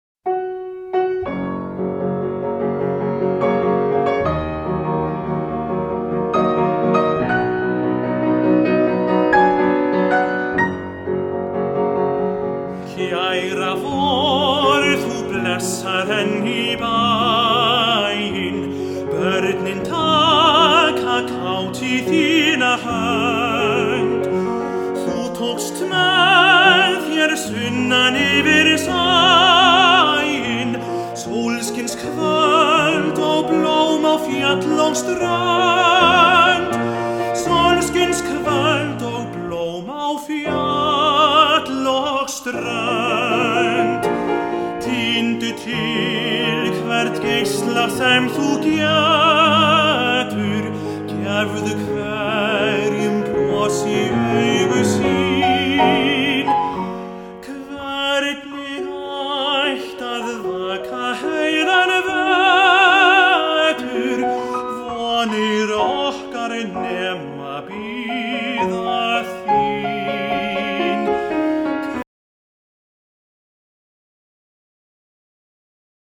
tenór
píanóleikari